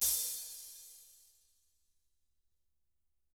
Index of /90_sSampleCDs/ILIO - Double Platinum Drums 2/Partition D/THIN A HATD